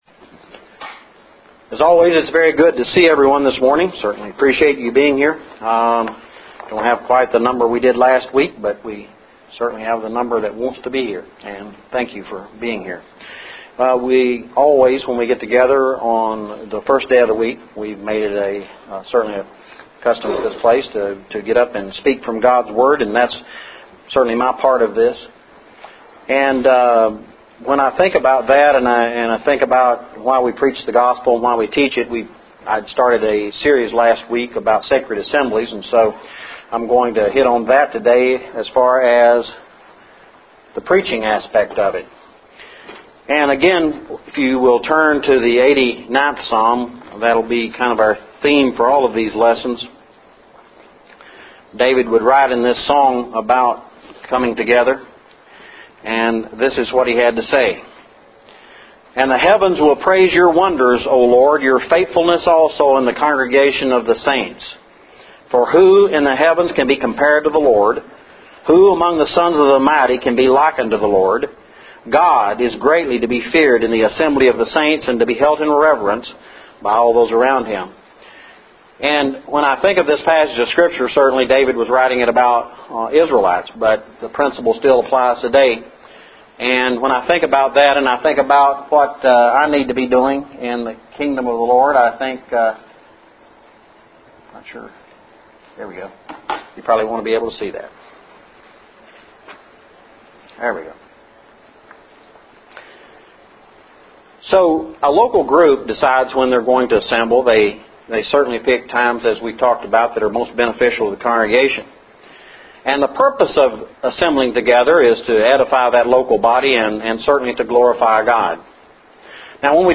Sacred Assemblies Lessons – 04/10/11 – Waynesville Church of Christ